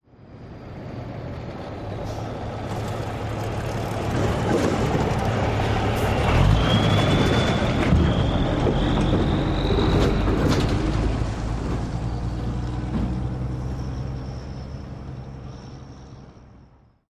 tr_dieseltruck_rrx_01_hpx
Diesel truck drives up and stops and idles, and passes over railroad tracks. Vehicles, Truck RR Crossing Engine, Motor